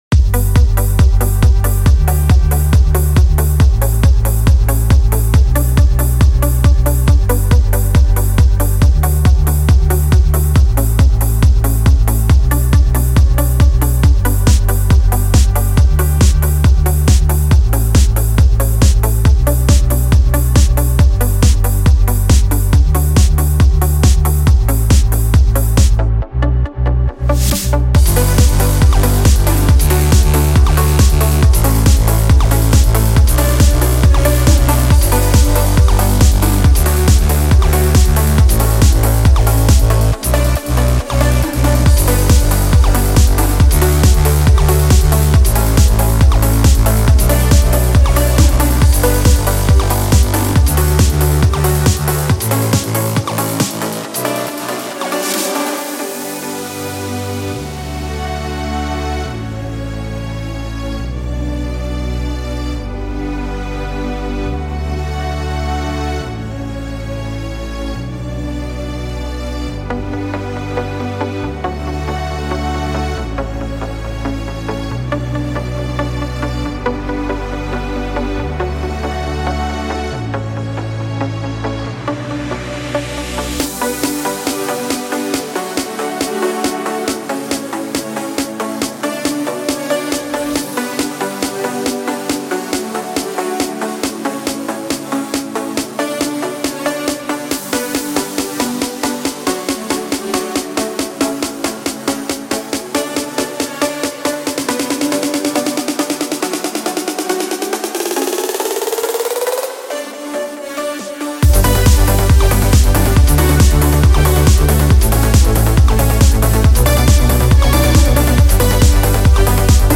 用于Trance音乐制作的终极硬打击鼓样本包
这些重击的Trance鼓样本制作出色，具有舞池破坏性的踢脚，强劲的圈套器，尖锐的踩hat和各种独特的打击乐器声音。